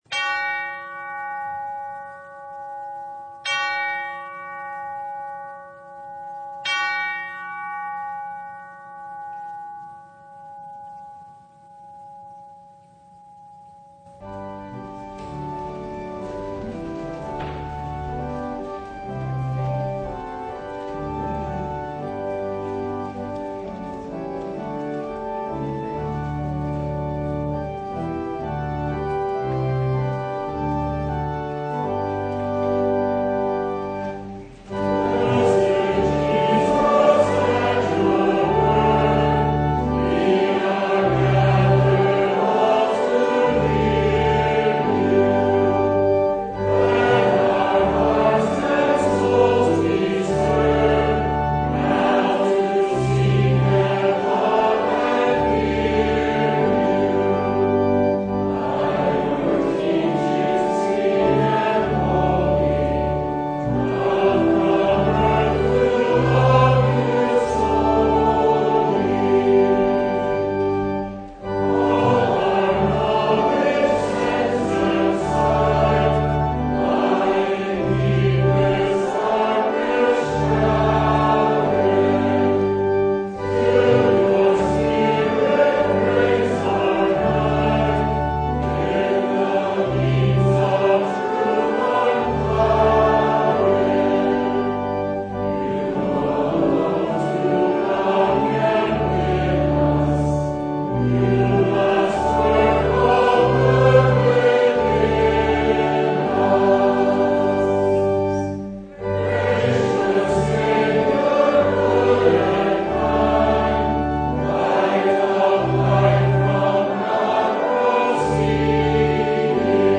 Sermon from The Presentation of the Augsburg Confession (2023)
Sermon Only